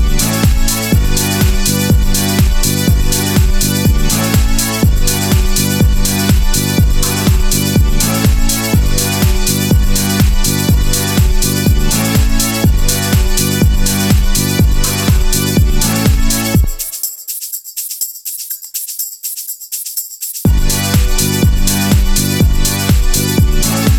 no Backing Vocals Pop (2000s) 4:02 Buy £1.50